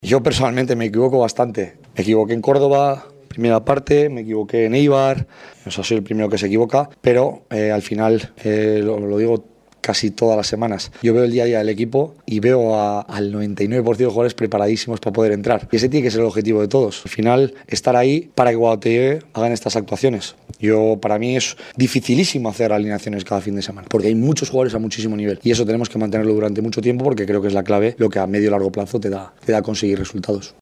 En la roda de premsa després del partit, l’entrenador ha destacat la feina del grup i ha qualificat la victòria de “merescuda”.